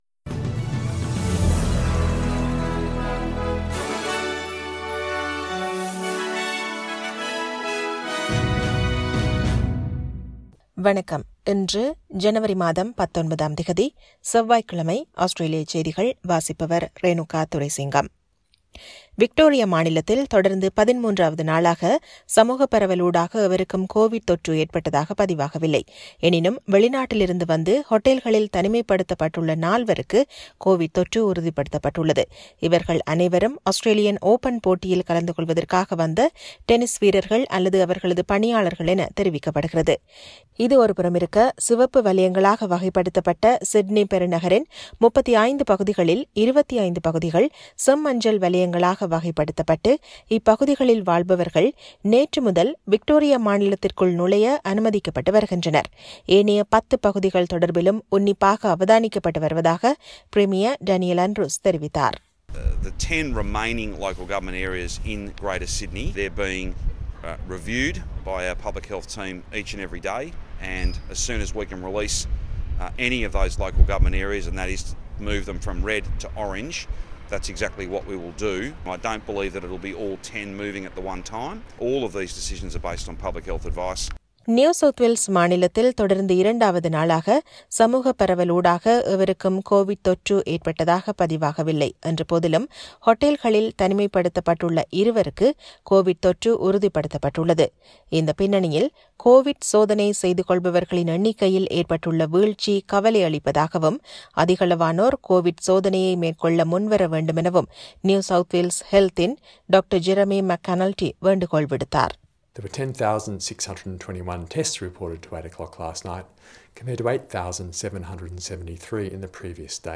Australian news bulletin for Tuesday 19 January 2021.